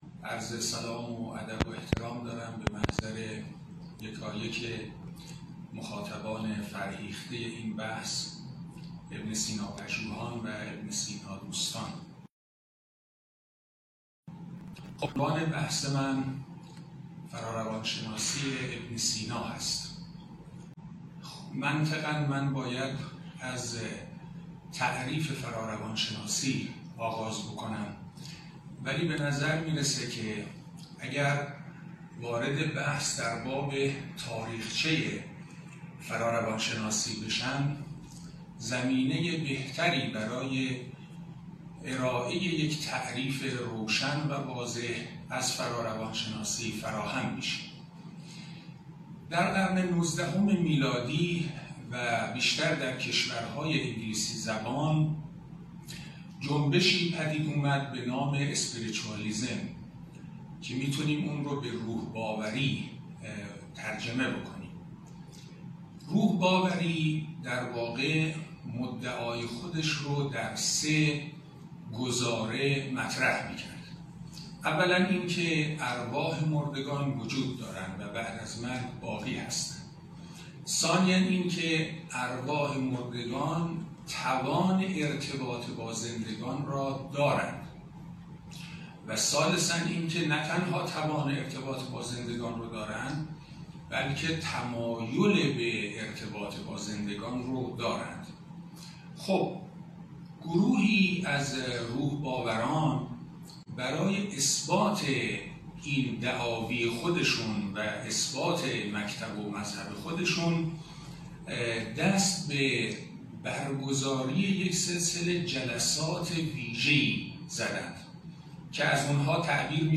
بیست‌ویکمین نشست از مجموعه درس‌گفتارهایی درباره بوعلی‌سینا
این درس‌گفتار به صورت مجازی از اینستاگرام شهر کتاب پخش شد.